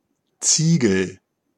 Ääntäminen
Synonyymit accident Ääntäminen France: IPA: /tɥil/ Haettu sana löytyi näillä lähdekielillä: ranska Käännös Konteksti Ääninäyte Substantiivit 1.